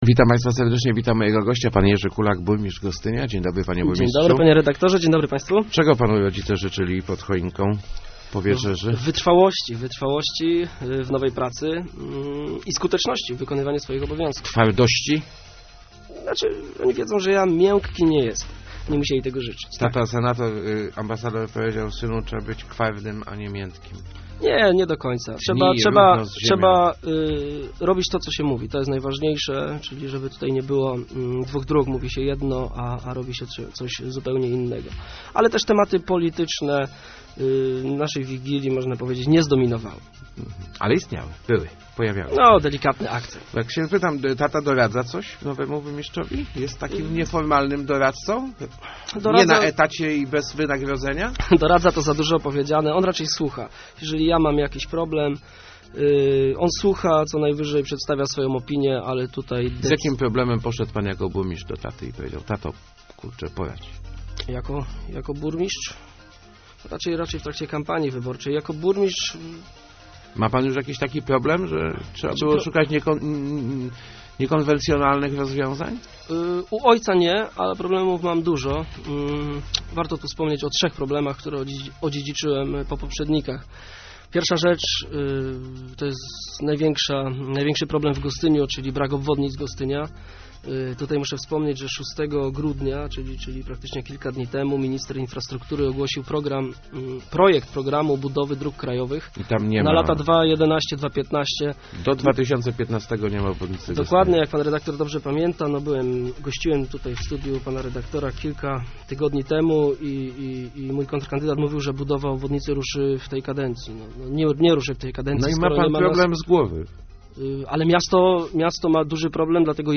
Na pocz�tku kadencji musz� poradzi� sobie z trzema najwa�niejszymi problemami - mówi� w Rozmowach Elki Jerzy Kulak, burmistrz Gostynia. Pierwszy to ci�g�y brak widoków na budow� obwodnic miasta. Drugim problemem jest pot�ny wzrost op�at za u�ytkowanie wieczyste oko�o 300 nieruchomo�ci.